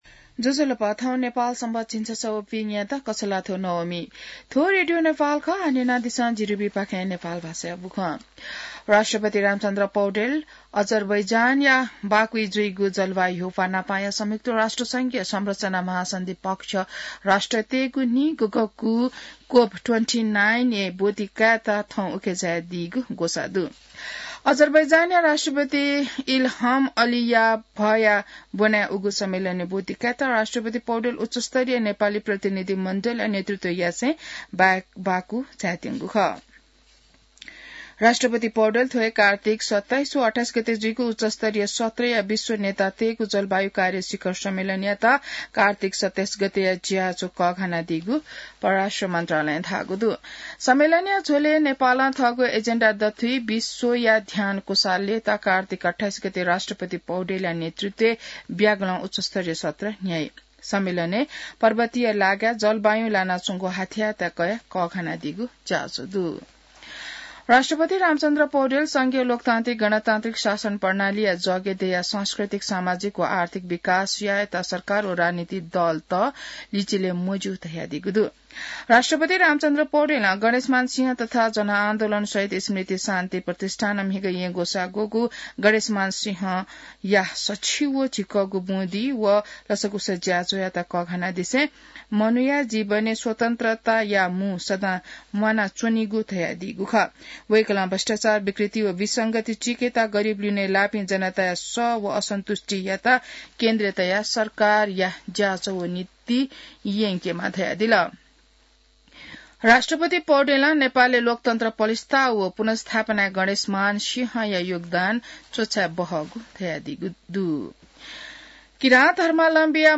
नेपाल भाषामा समाचार : २६ कार्तिक , २०८१